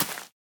Minecraft Version Minecraft Version 1.21.5 Latest Release | Latest Snapshot 1.21.5 / assets / minecraft / sounds / block / bamboo / sapling_place4.ogg Compare With Compare With Latest Release | Latest Snapshot
sapling_place4.ogg